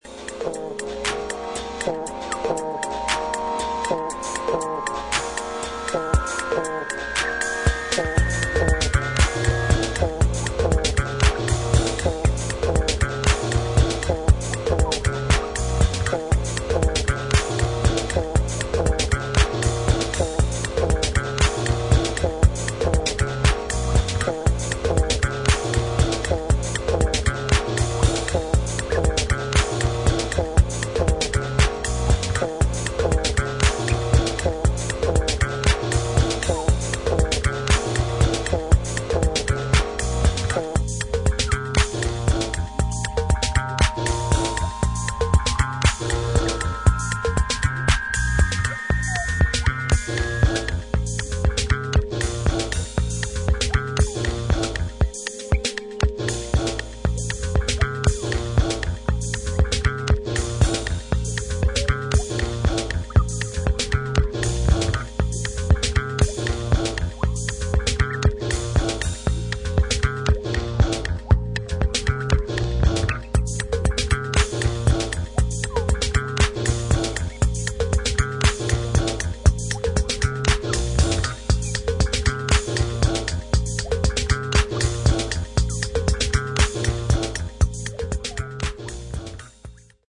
有機的な音と電子音の印象的な融合をハウスの領域で表現した一枚